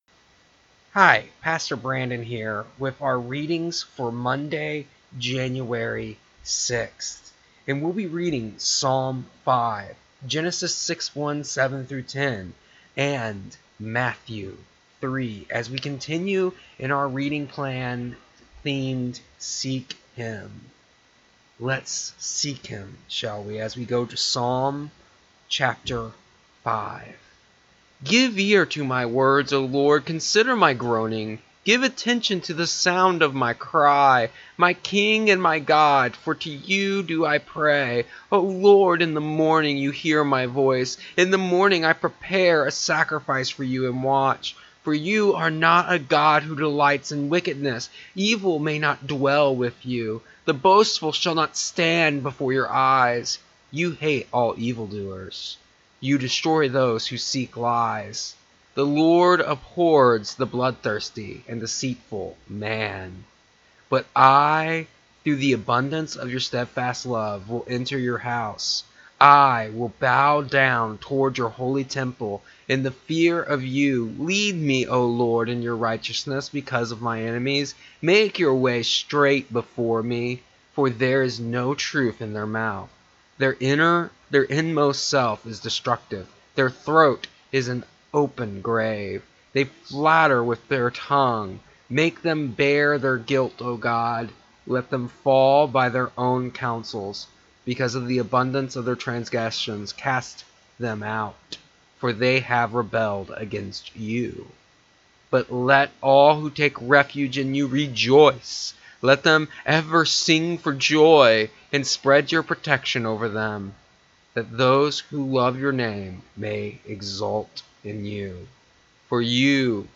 Our readings today were: Psalm 5 Genesis 6:1-7:10 Matthew 3